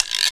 GUIRO LONG.wav